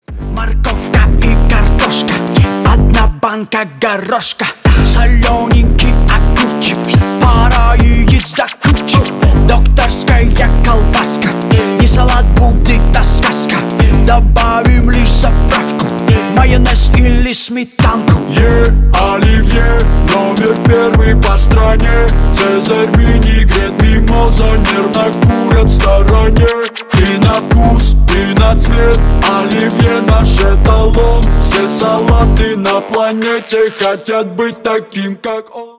весёлые # кавер # пародия